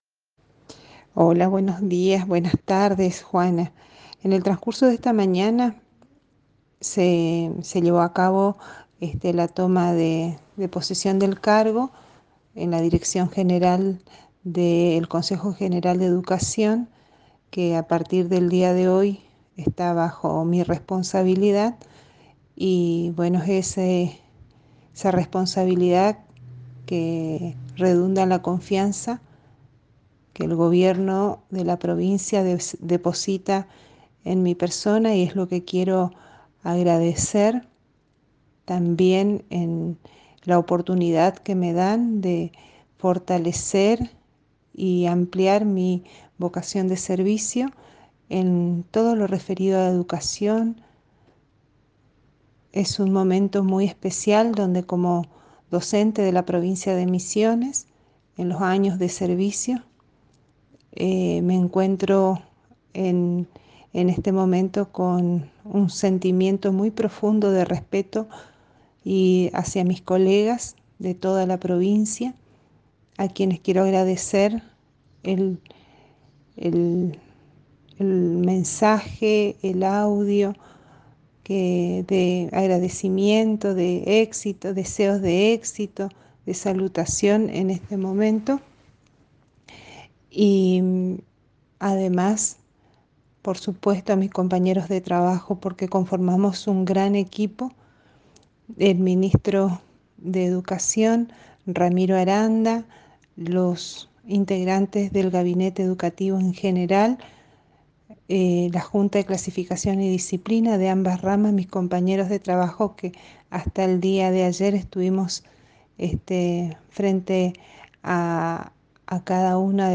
En diálogo con la ANG la nueva Directora General de C.G.E Daniela López comentó